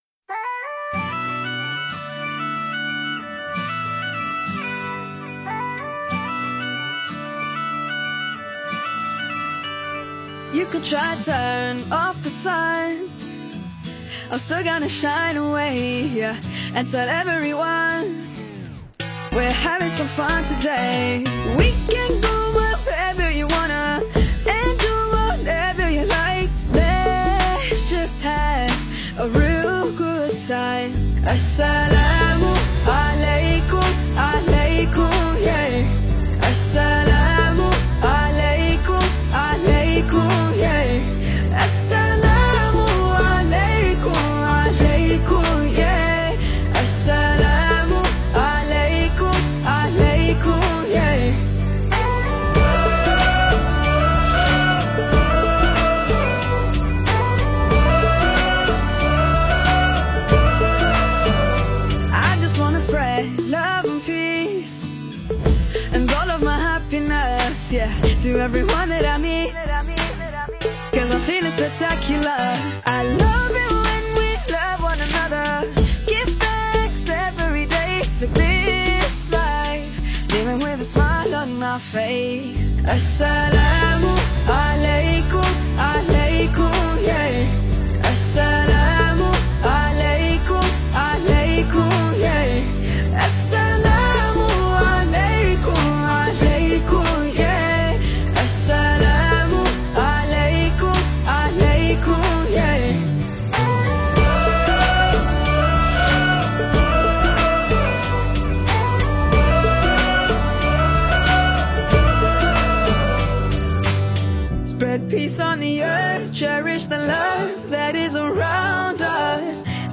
Qaswida You may also like